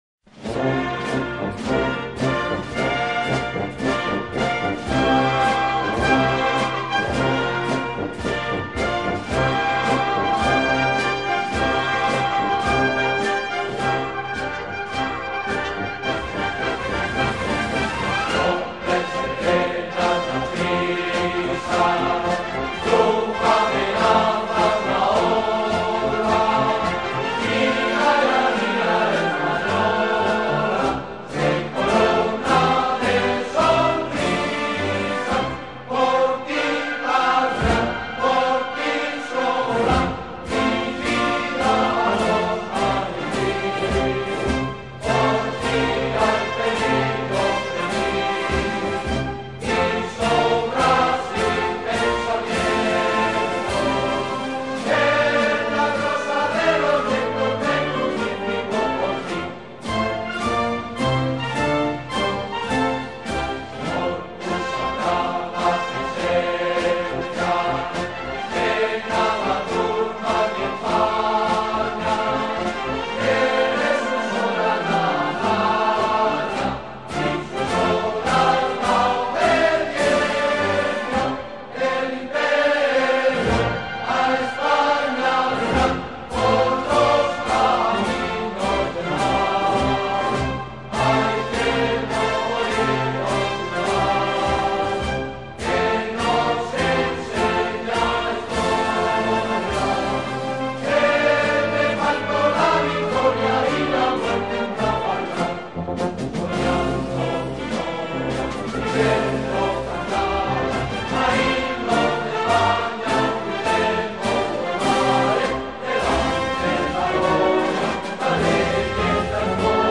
Himnos
Todos los HIMNOS MILITARES